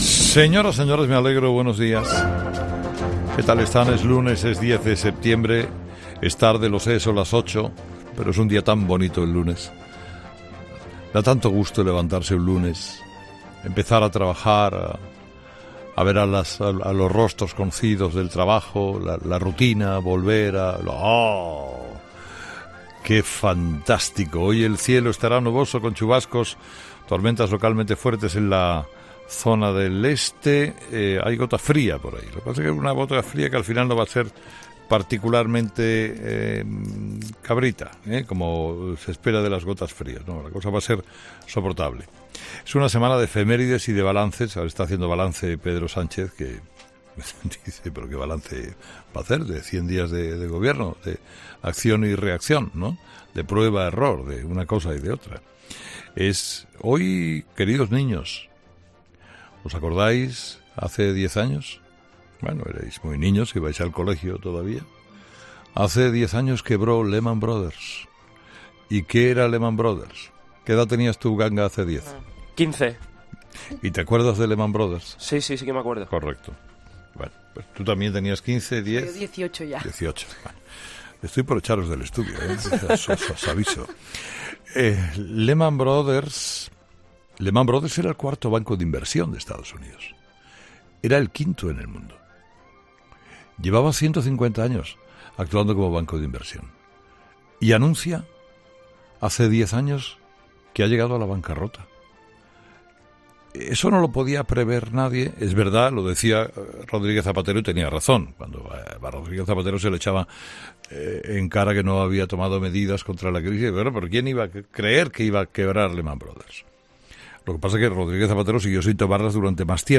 Monólogo de Herrera del lunes 10 de septiembre